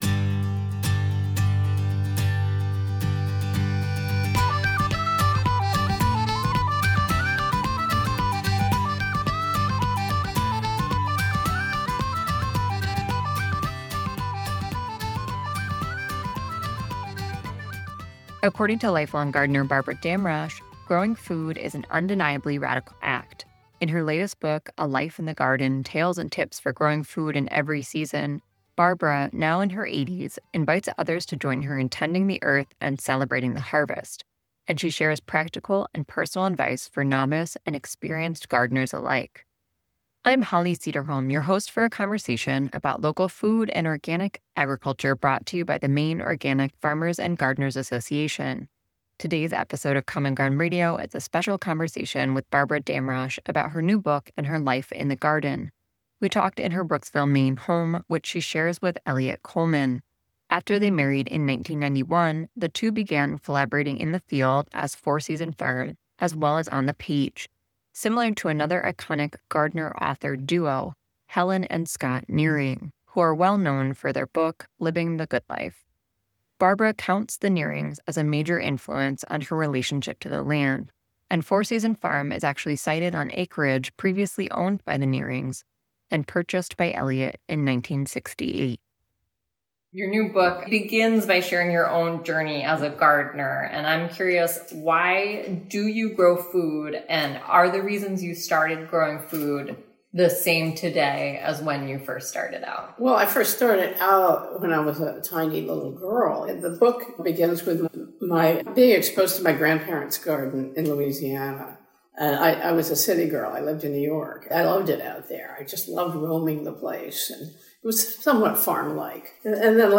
Common Ground Radio is an hour-long discussion of local food and organic agriculture with people here in the state of Maine and beyond.
The post Common Ground Radio 5/9/24: Organic Gardening Q&A first appeared on WERU 89.9 FM Blue Hill, Maine Local News and Public Affairs Archives.